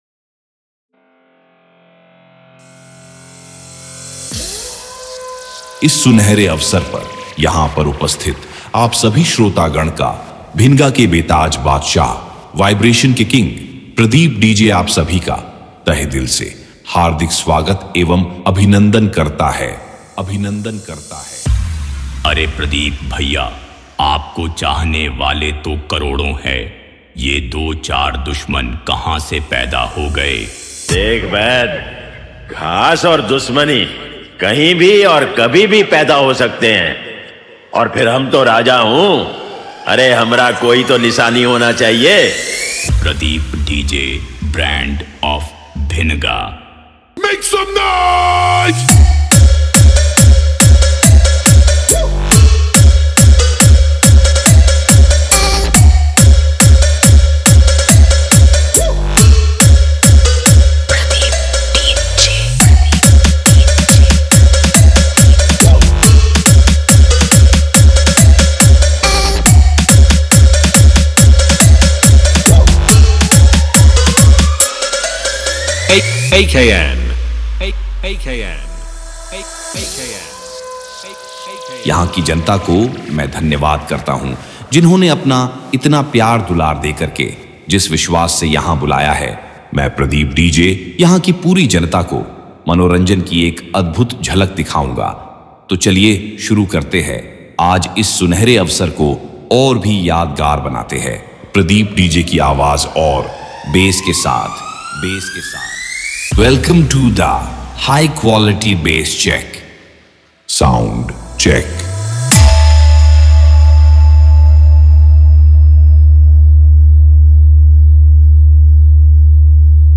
Competition Intro DJ Beat, Stage Show DJ Mix
Bass Boosted DJ Remix
Party DJ Intro